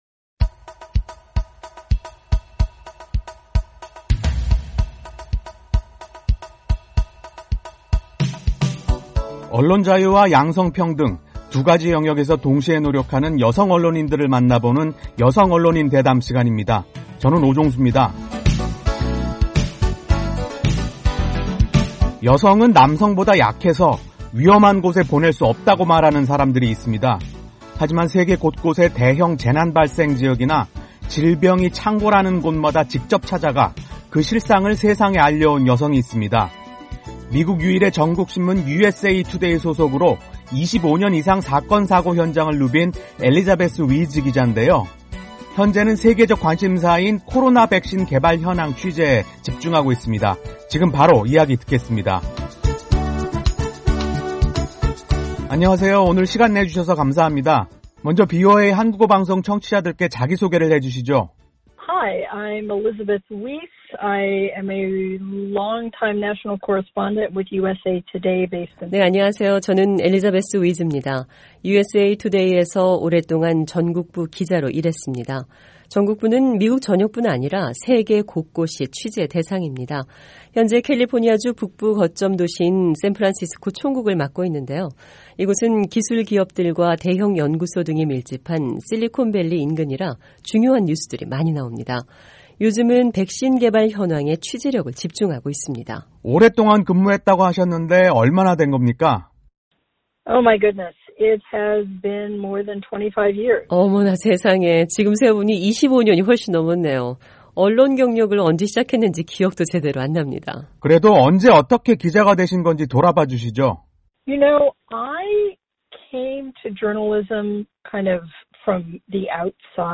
[여성 언론인 대담] 코로나 백신 집중취재